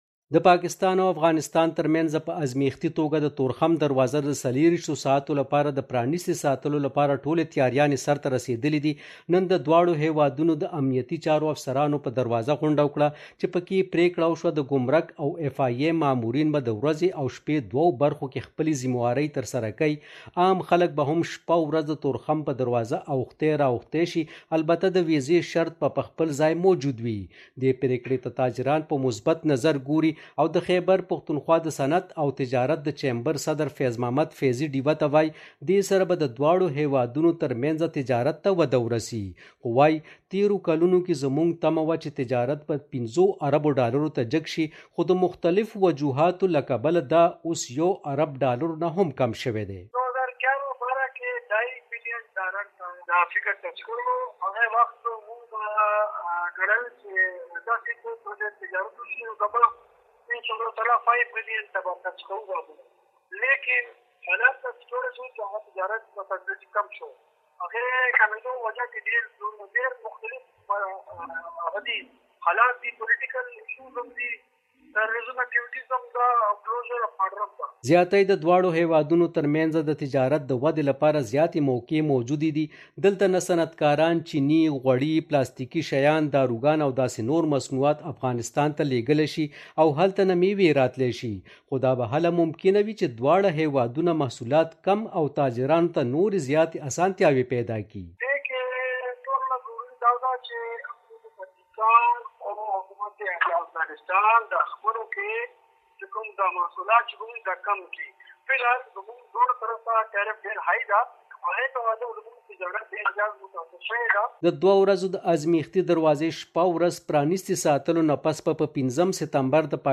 پېښور —